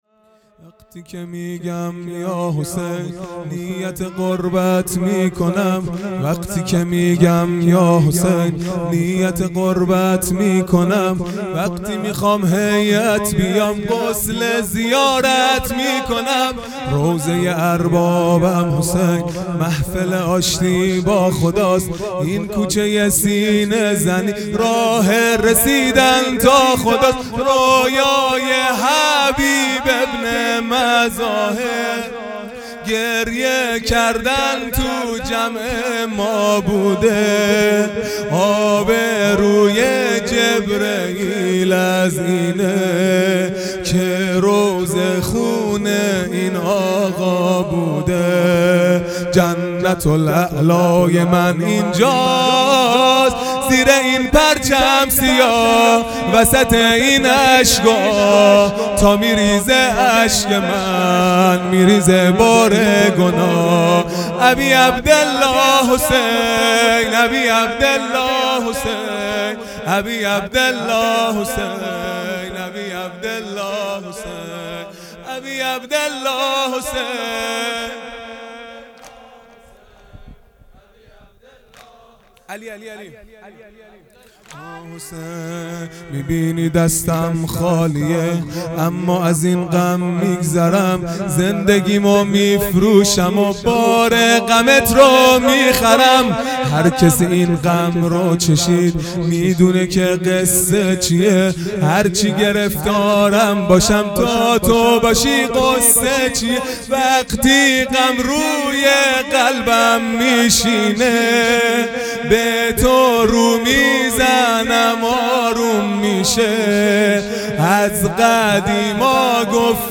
خیمه گاه - هیئت بچه های فاطمه (س) - واحد | وقتی که میگم یاحسین نیت غربت میکنم